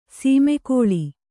♪ samagoḷ